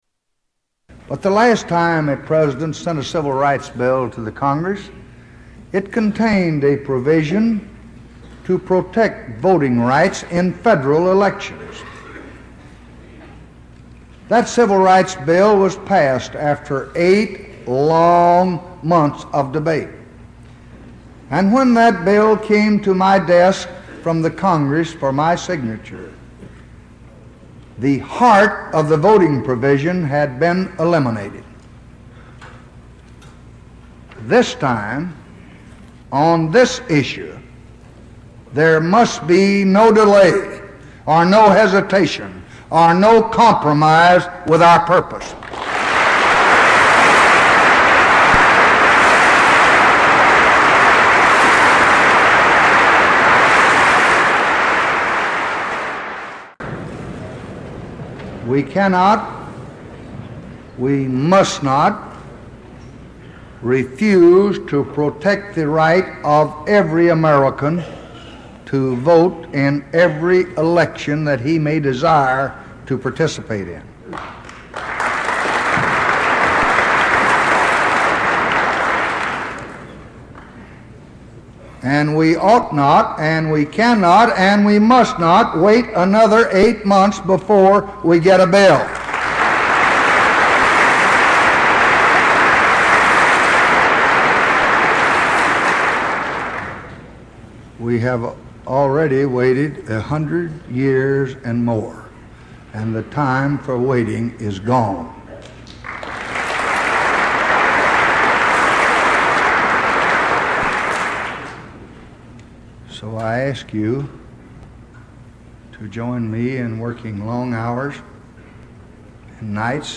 Tags: Historical Lyndon Baines Johnson Lyndon Baines Johnson clips LBJ Renunciation speech